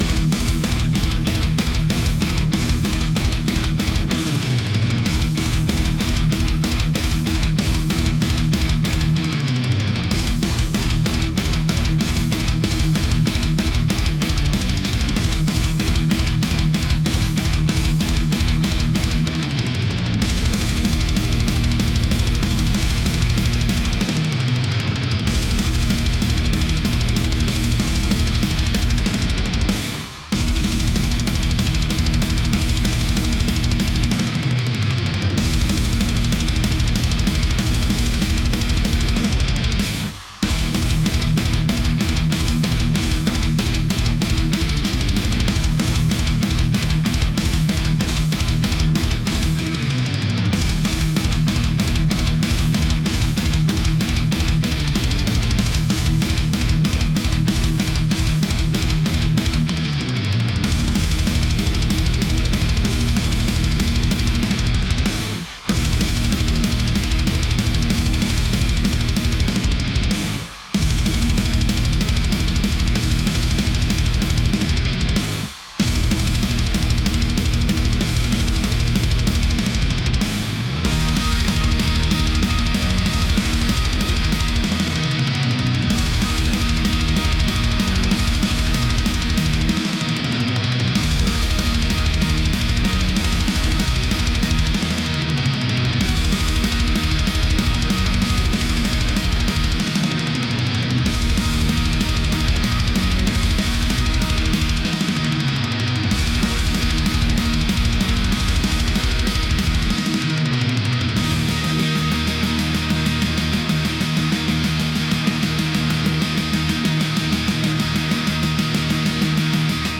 metal | heavy | intense